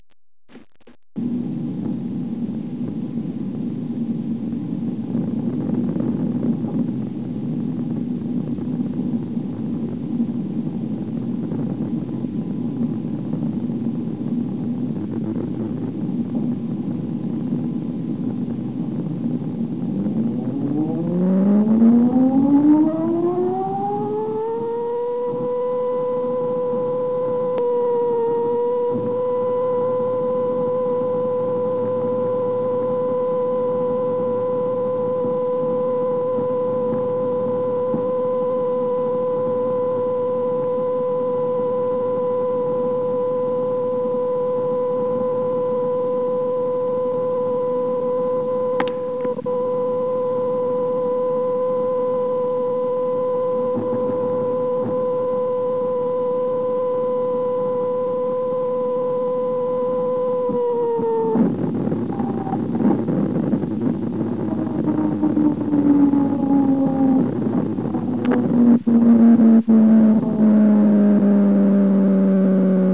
Hear the attack.
1. The siren goes off
siren.wav